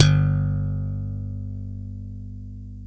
Index of /90_sSampleCDs/East Collexion - Bass S3000/Partition A/SLAP BASS-B